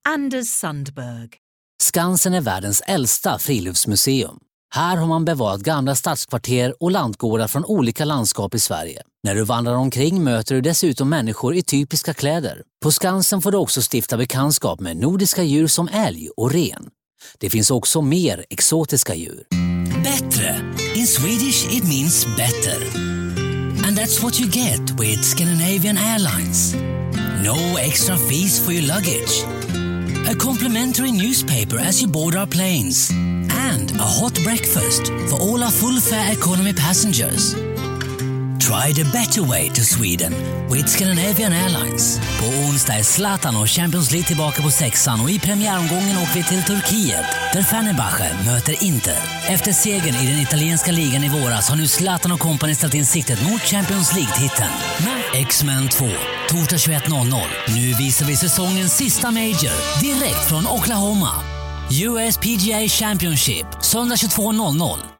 Swedish actor and voiceover artist